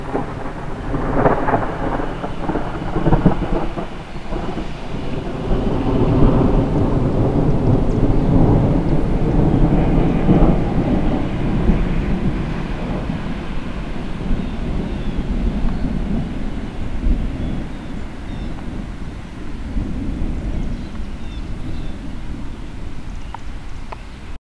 2013-06-02 – Thunder [Audio Clip]
Stereo